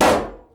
sounds_balloon_pop.ogg